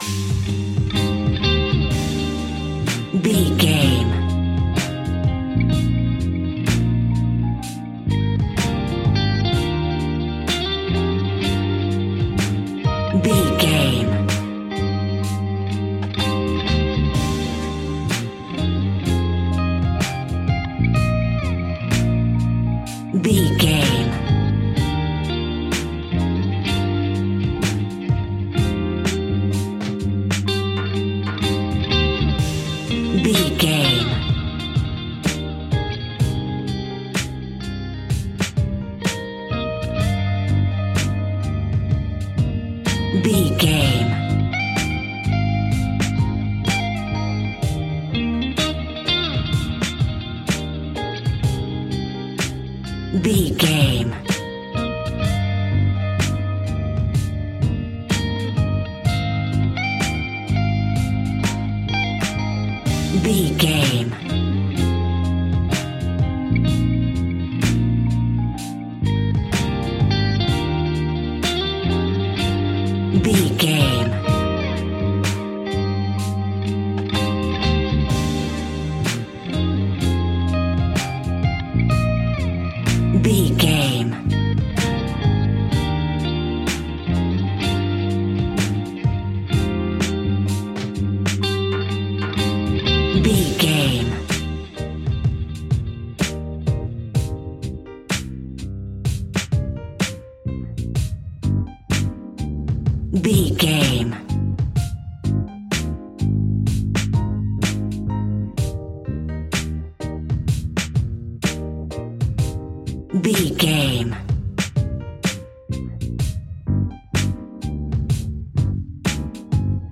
Ionian/Major
D♯
chilled
laid back
Lounge
sparse
new age
chilled electronica
ambient
atmospheric